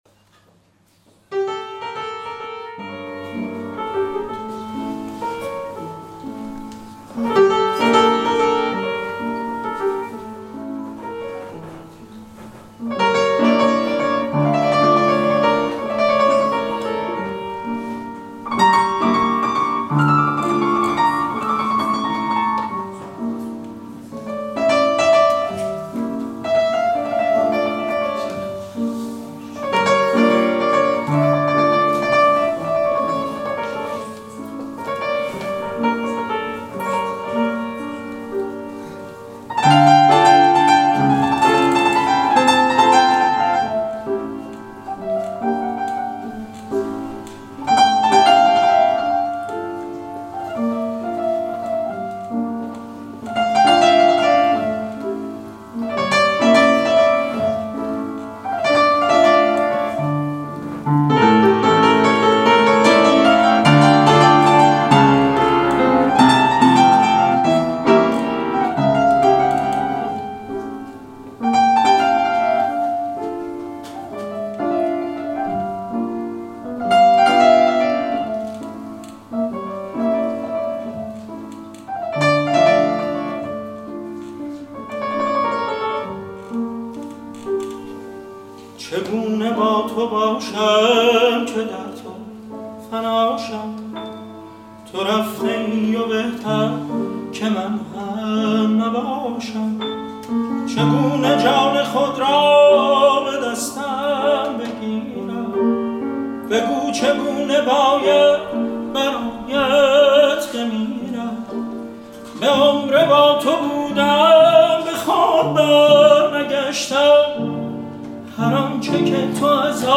به گزارش سینماسینما، محمد رحمانیان به همراه گروه تئاتری اش، در محرم ۹۴، نمایش نامه خوانی «روز واقعه» به قلم بهرام بیضایی را برگزار کردند.
در این نمایش نامه خوانی، علی زند وکیلی هم قطعه ای زیبا اجرا کرد که گروه، این نمایش را به امام حسین (ع) تقدیم کردند.